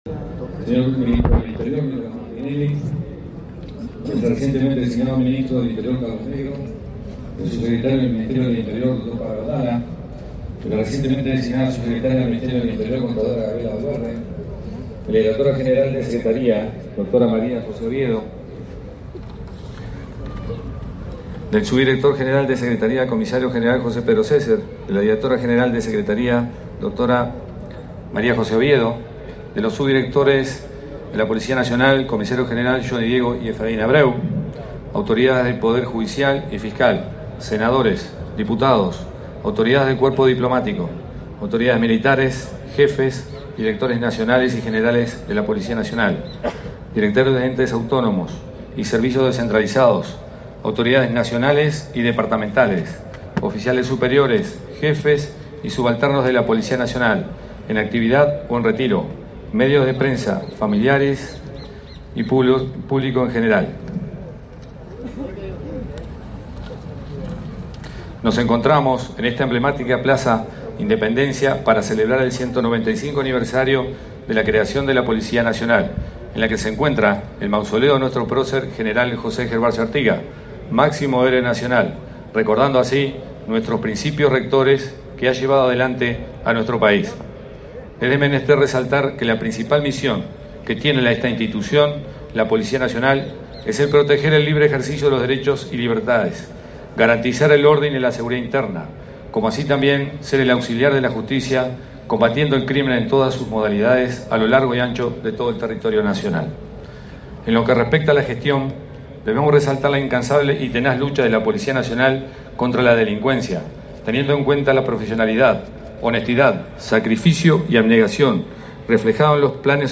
Palabras del director de la Policía Nacional, José Azambuya
Con la presencia del ministro del Interior, Nicolás Martinelli, se realizó la conmemoración por el 195.° aniversario de la Policía Nacional.
En el evento disertó el director, José Azambuya.